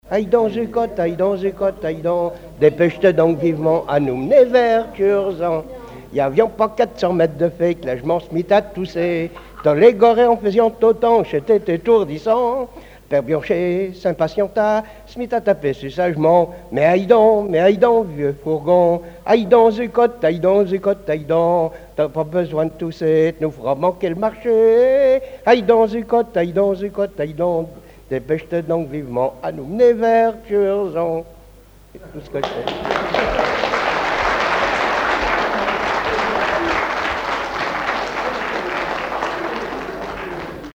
Patois local
Regroupement de témoins ; chanteuses, chanteurs, musiciens
Pièce musicale inédite